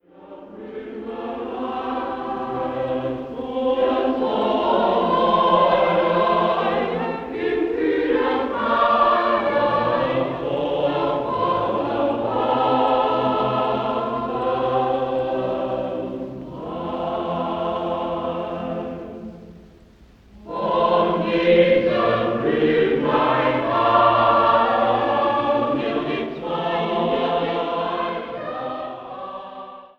Karl Straube und Günther Ramin mit dem Thomanerchor Leipzig in frühesten Grammophon- und Rundfunkaufnahmen
CD-Beilage mit frühesten Schallaufnahmen des Thomanerchores seit 1928